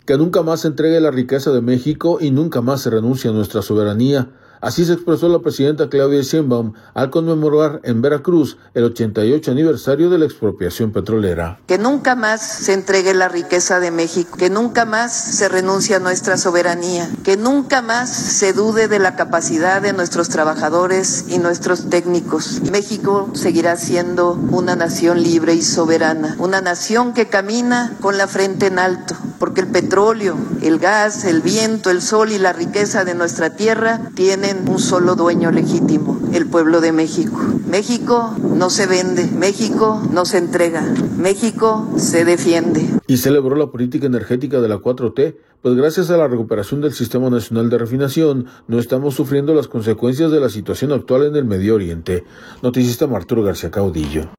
Que nunca más se entregue la riqueza de México y nunca más se renuncie a nuestra soberanía, así se expresó la presidenta Claudia Sheinbaum al conmemorar, en Veracruz, el 88 aniversario de la Expropiación Petrolera.